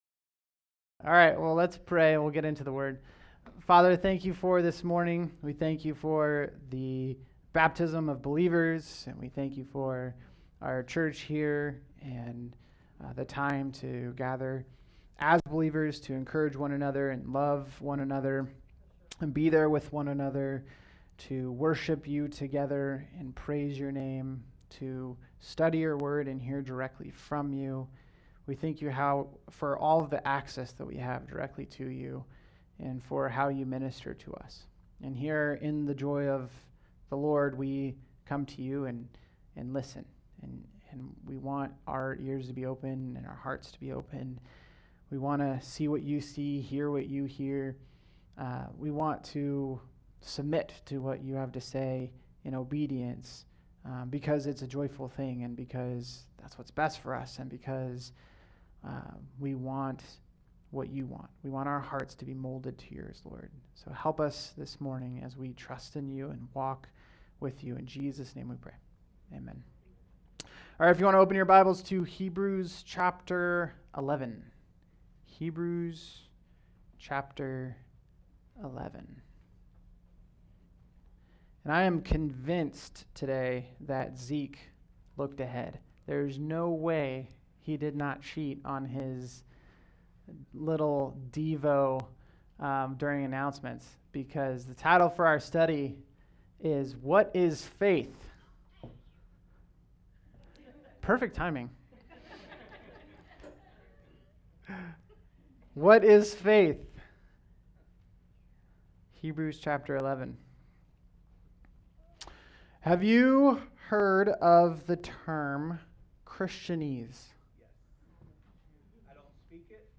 All Sermons What is Faith?